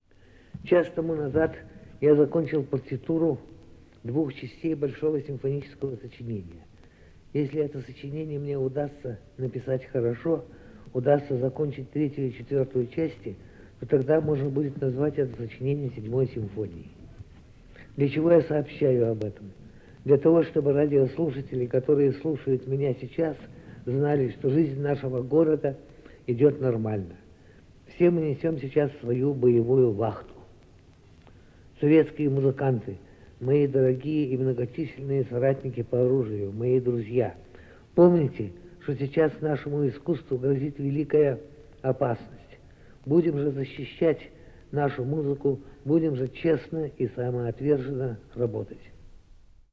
ShostakovichRadio1941.ogg.mp3